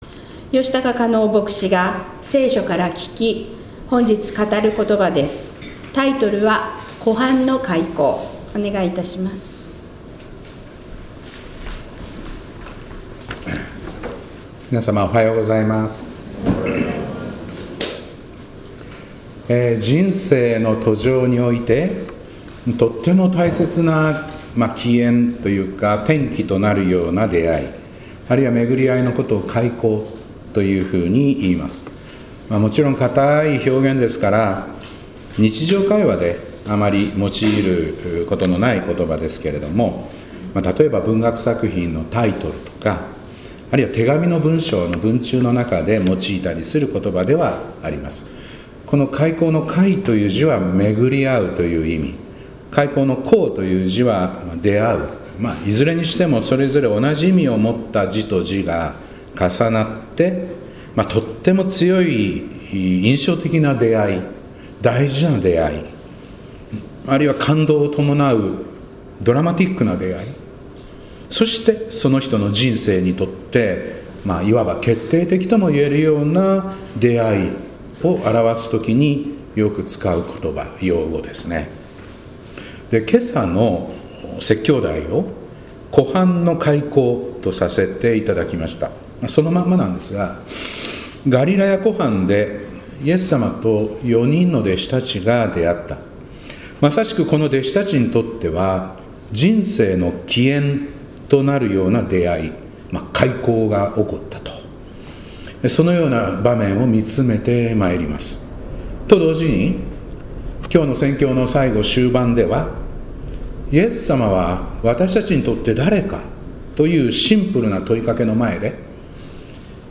2025年1月12日礼拝「湖畔の邂逅（かいこう）」